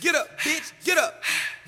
Vox
SouthSide Chant (19).wav